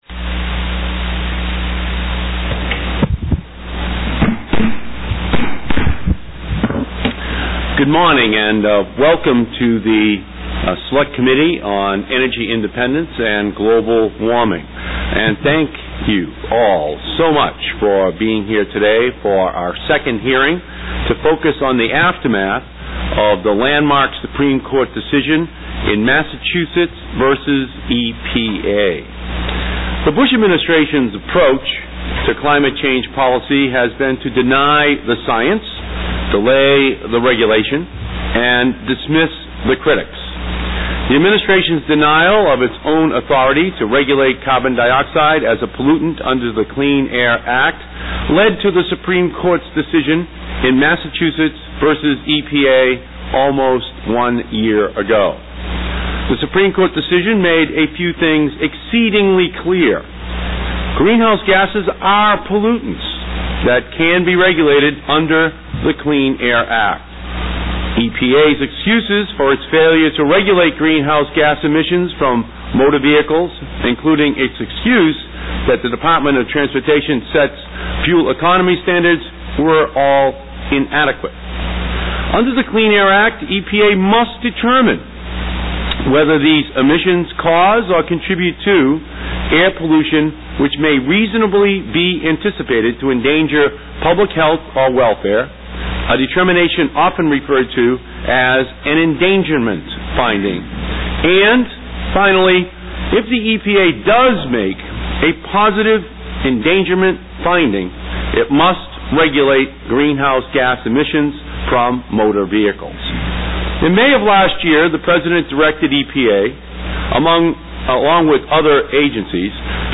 To listen to an AUDIO file of this hearing, please CLICK HERE.
WHERE: 1334 Longworth House Office Building, Washington, DC